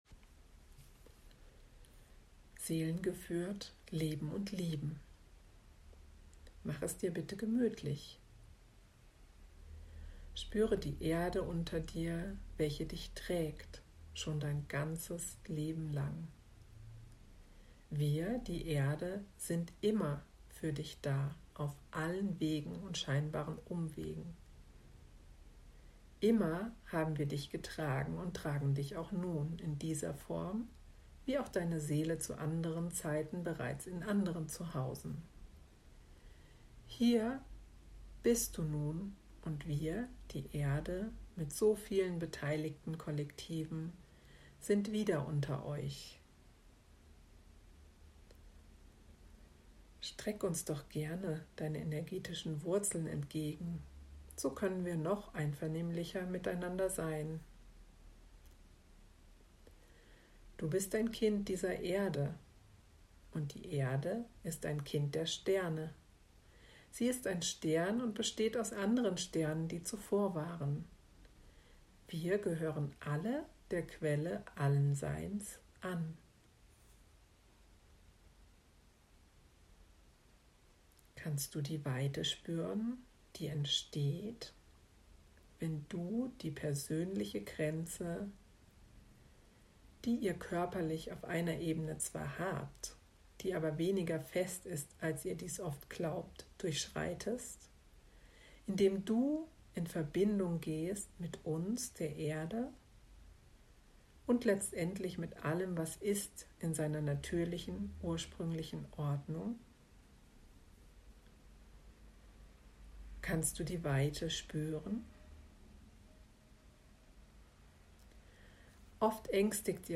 Im zweiten Audio findest du die Meditation ohne Intro –damit du sie später unmittelbar hören kannst.